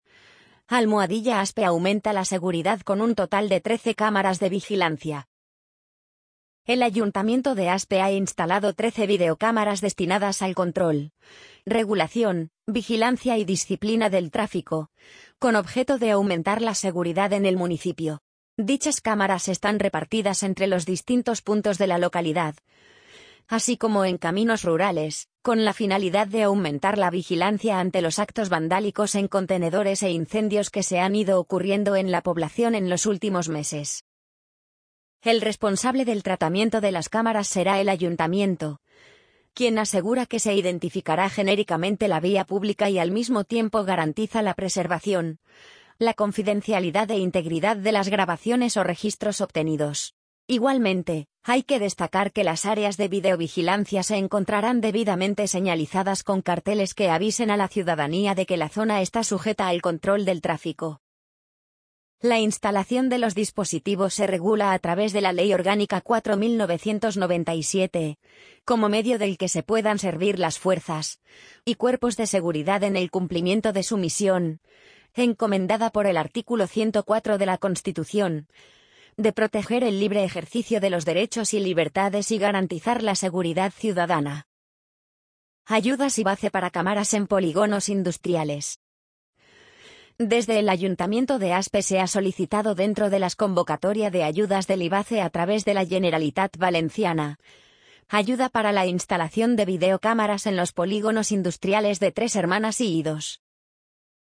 amazon_polly_48557.mp3